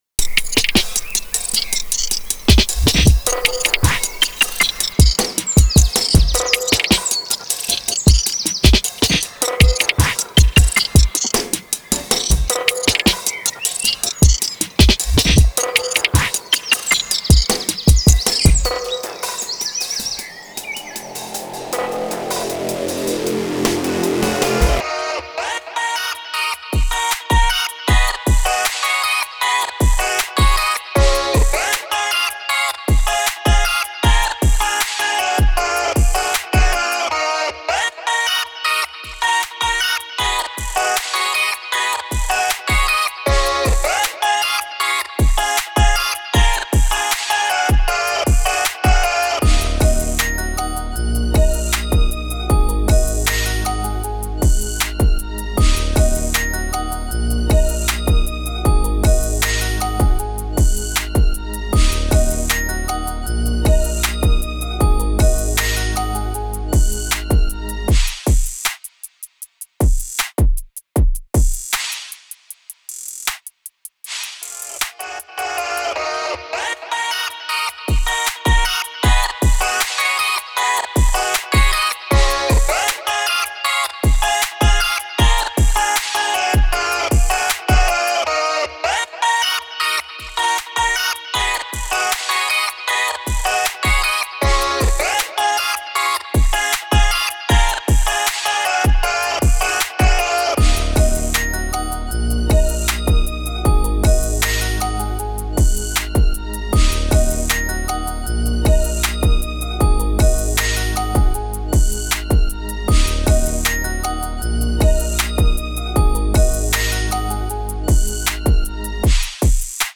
A light 62 BPM piece in C minor.